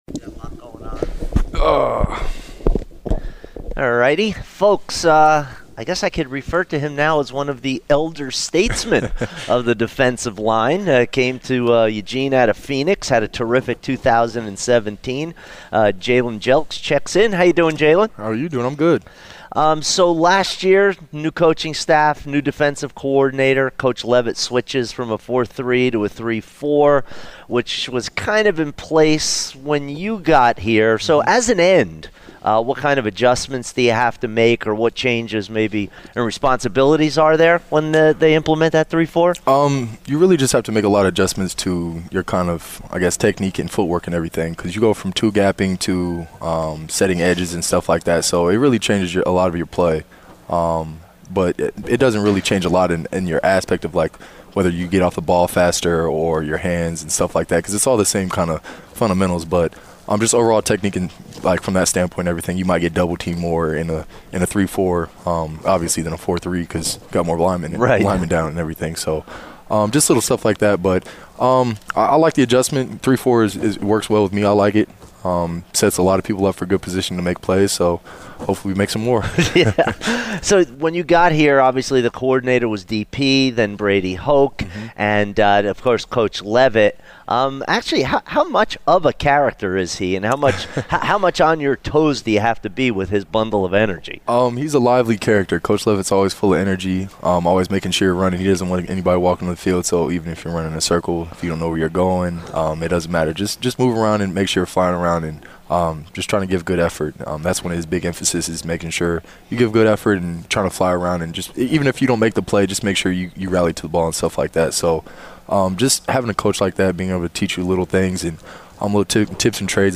Interview, Oregon Media Day 2018